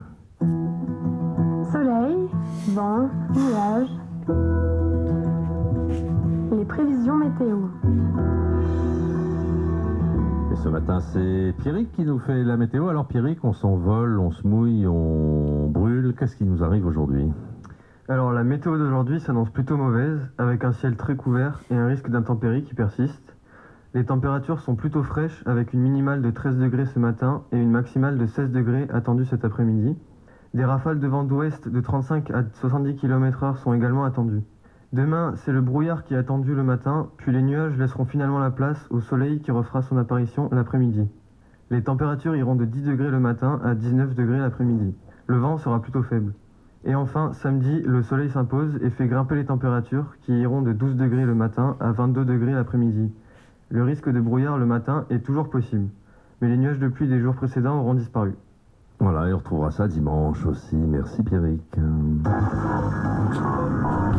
2e bulletin mis en voix